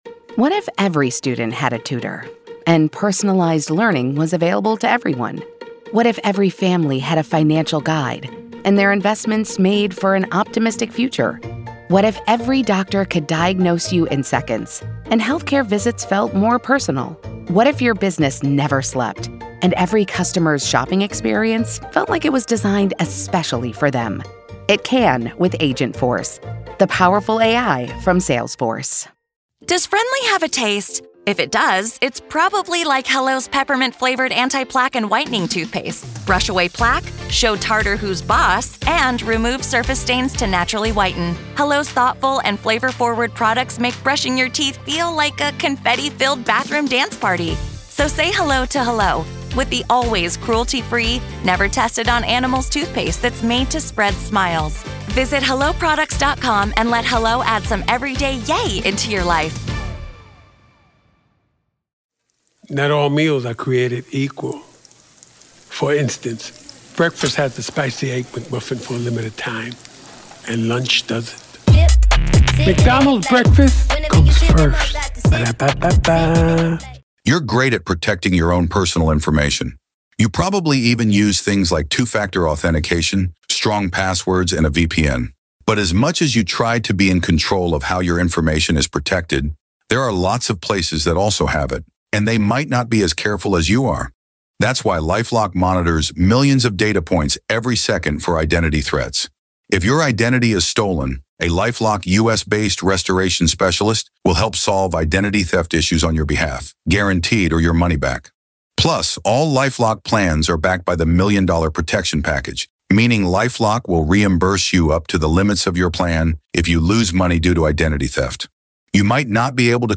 True Crime Today | Daily True Crime News & Interviews / Are The Menendez Brothers Playing On The Public's Sympathy, Or Has Their Time Come For Freedom?